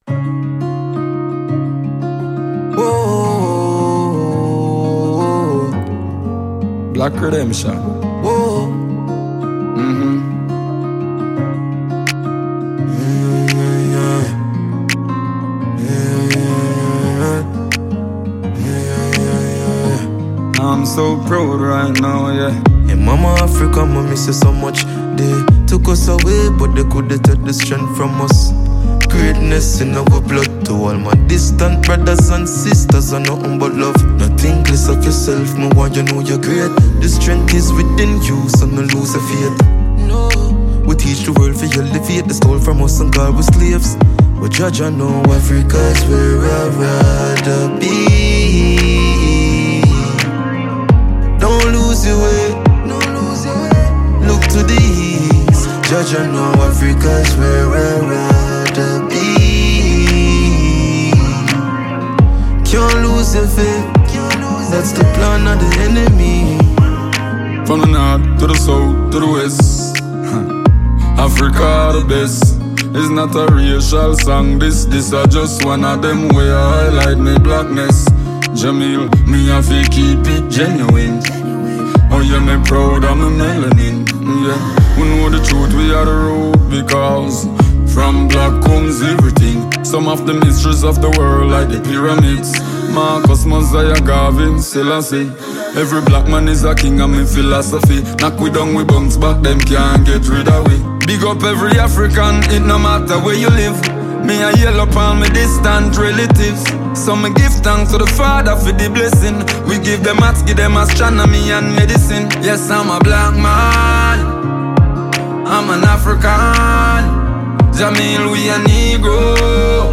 Award-winning Jamaican dancehall musician